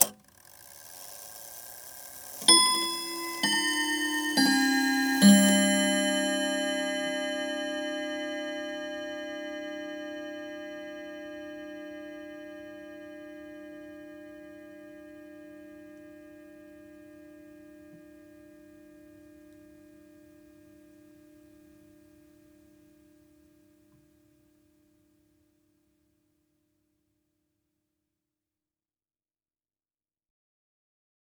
Clock Quarter-Hour Chime.wav